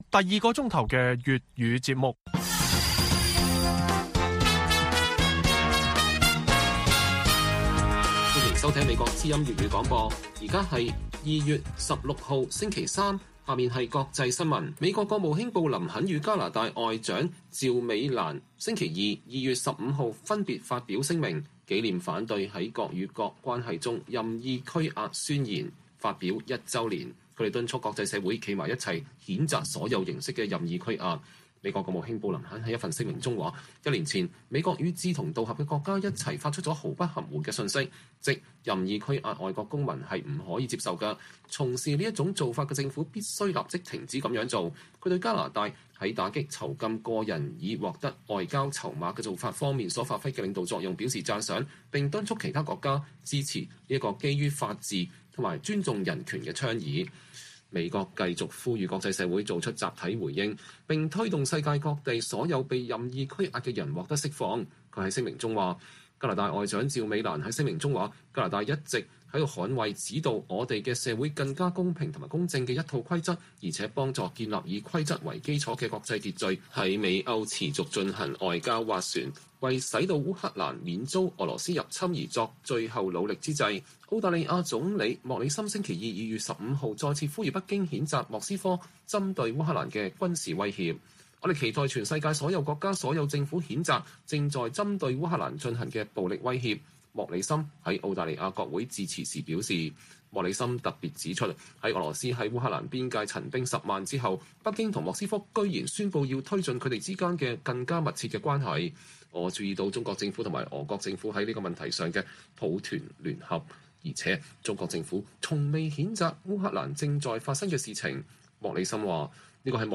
粵語新聞 晚上10-11點: 香港醫管局實施”疫苗通行證” 未打疫苗可被解僱 工會批做法不合理